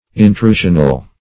intrusional - definition of intrusional - synonyms, pronunciation, spelling from Free Dictionary
Intrusional \In*tru"sion*al\, a.